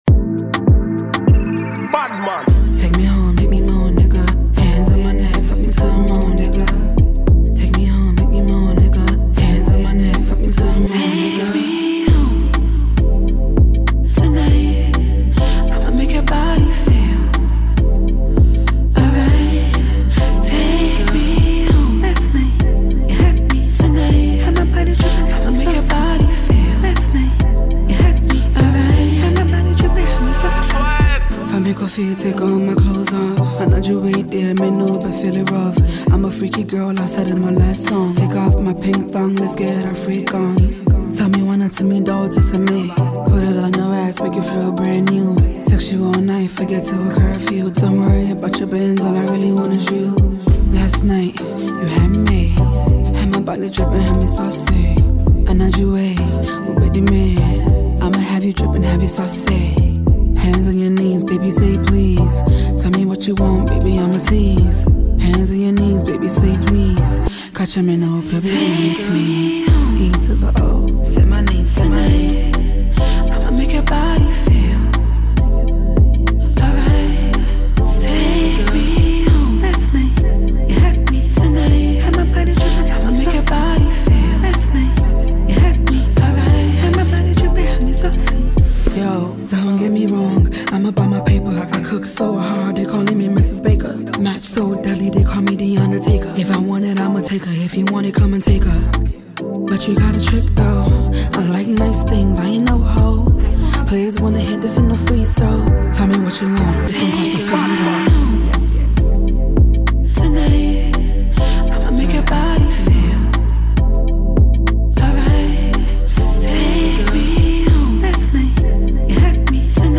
the exciting new afrobeat release from Ghana’s music scene.
Genre: Hiphop